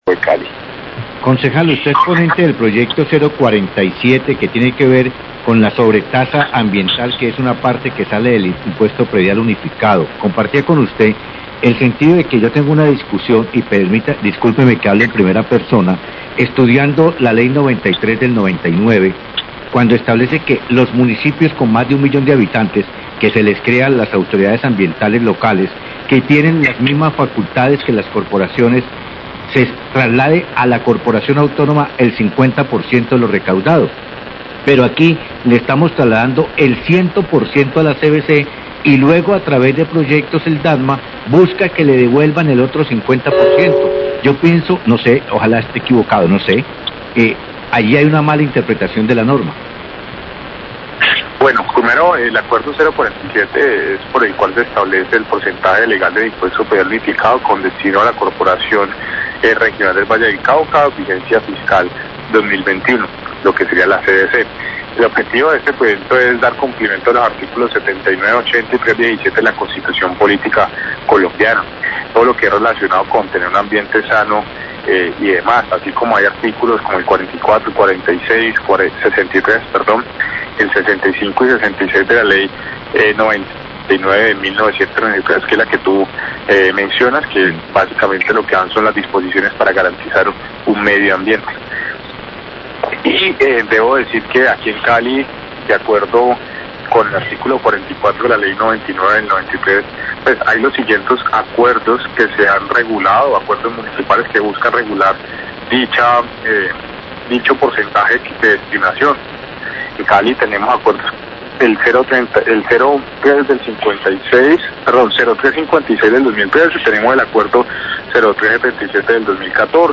Radio
Concejal Juan Martín Bravo habla sobre el proyecto de acuerdo que se discutirá, y del cual es ponente, en el concejo de Cali para modificar el porcentaje de recursos del impuesto predial que se destinan a la CVC.  Manifestó que no se encuentra de acuerdo con la transferencia de recursos que se están haciendo en Cali.